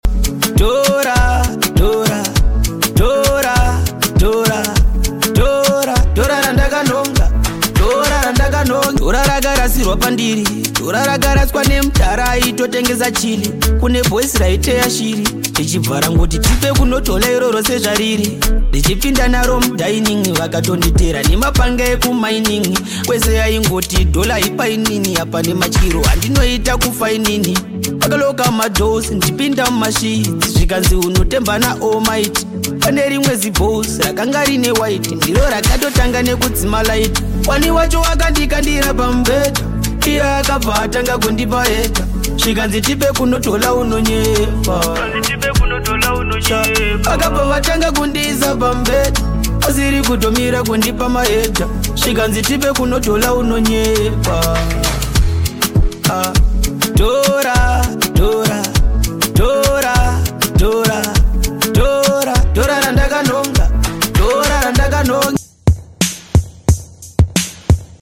remix - Horror